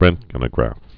(rĕntgə-nə-grăf, -jə-, rŭnt-)